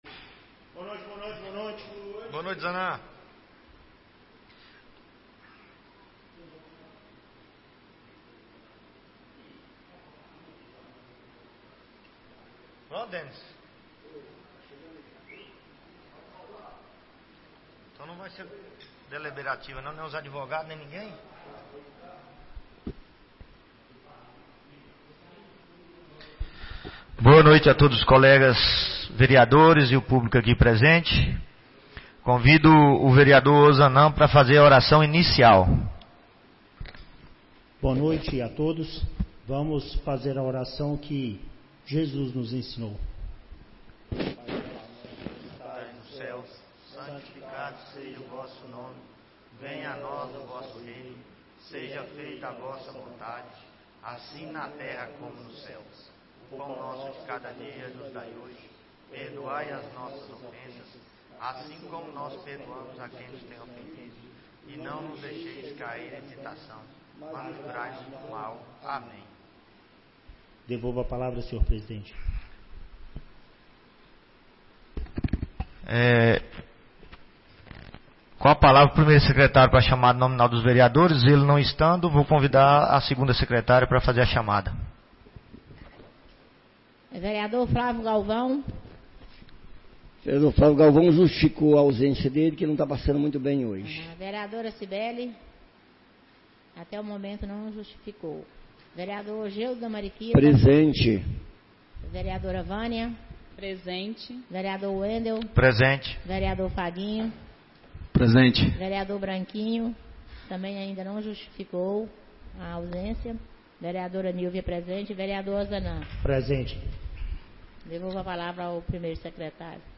23ª Reunião Ordinária da 4ª Sessão Legislativa da 15ª Legislatura - 12-08-24